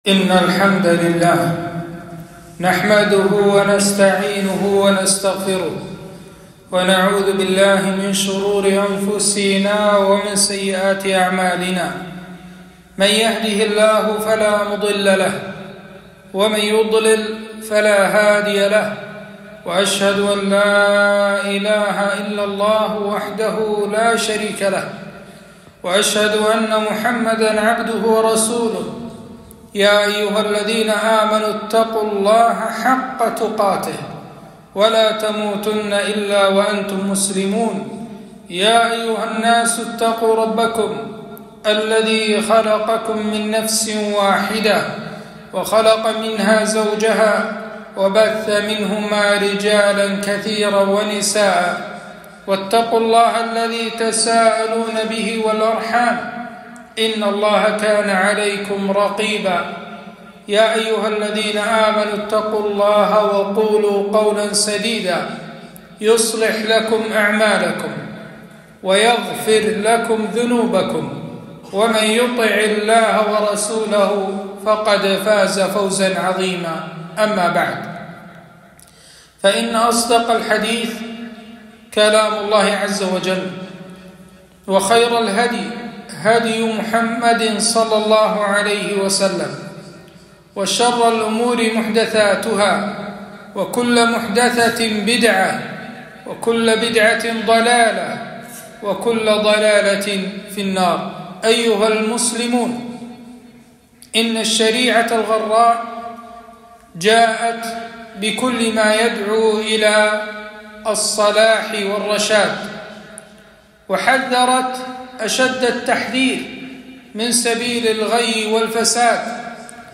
خطبة - والله لايحب الفساد